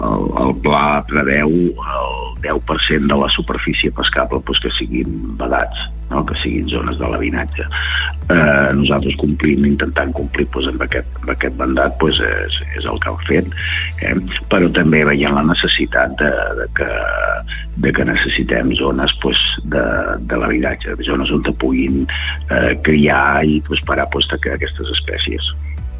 Entrevistes SupermatíPalamósSupermatí